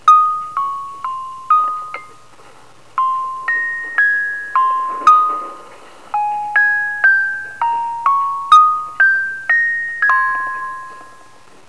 私が今までに収録してきた、ささやかな車内放送チャイム集です。
気動車オルゴール[jrdc-a.wav/251KB]
このオルゴールは、1998年9月の北海道旅行の際、札幌→釧路の夜行列車「おおぞら13号」で 収録したものです。 なお、これはスハネフ14-504の車掌室で鳴らされたものです。
なお、ここで掲載しているのは、放送の前後で切れてしまったのをつなぎあわせたものです。 車内が静かでしたから、あまり気にならないとは思いますが…
つなぎあわせといいながらも、ちゃんとフルコーラスにしていますから、勘弁してくださいね…